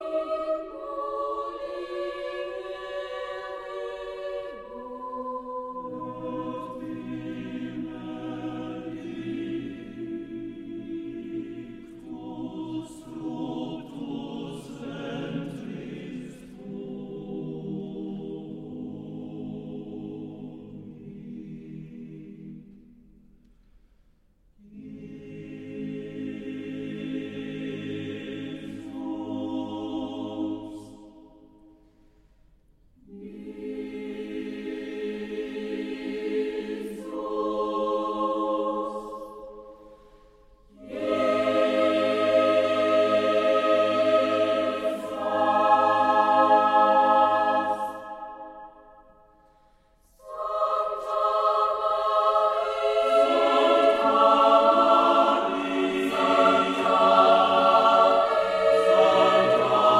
• Sachgebiet: Klassik: Chormusik